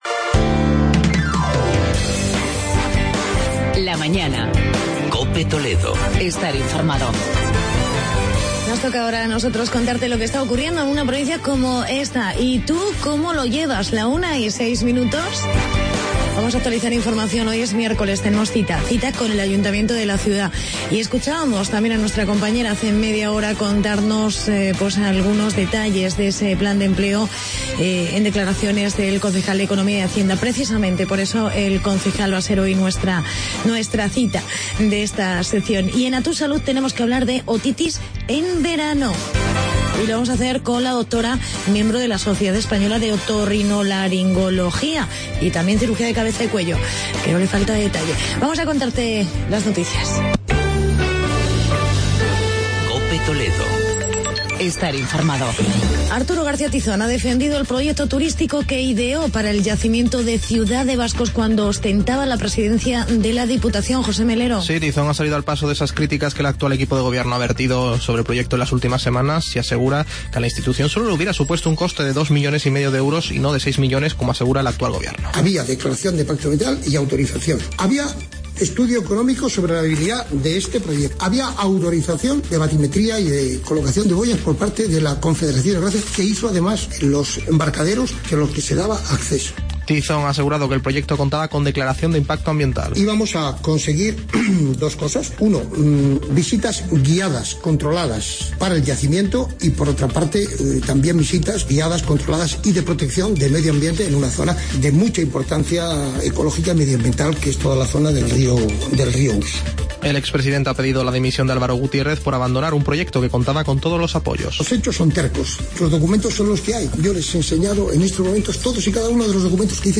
Entrevista con el concejal Arturo Castillo sobre Planes de Empleo y datos del paro de Talavera de la Reina y en "A...